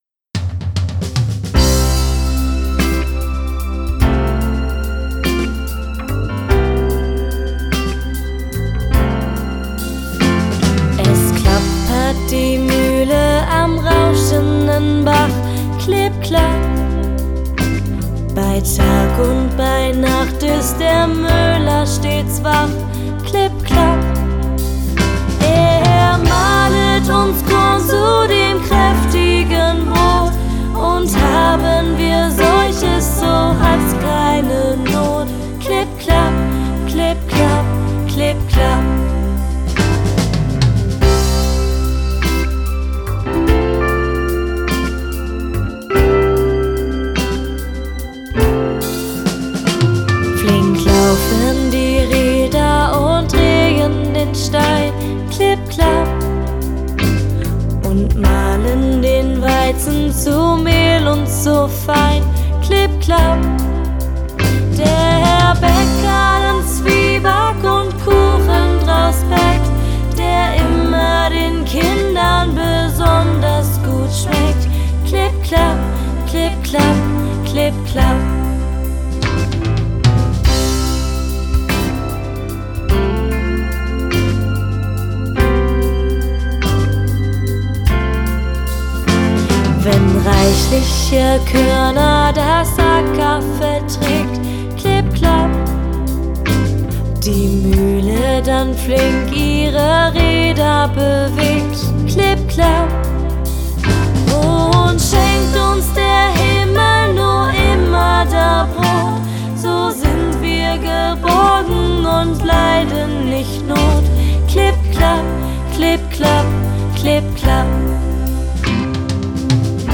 Traditionelle Lieder
Arrangement: ClassicRocks Musikernetzwerk
„Es klappert die Mühle am rauschenden Bach" ist ein klassisches deutsches Volkslied, dessen genaue Entstehungszeit unbekannt ist.
Die rhythmische Lautmalerei „Klipp klapp" ahmt das Geräusch des Mühlrads nach und macht das Lied besonders leicht zum Mitsingen.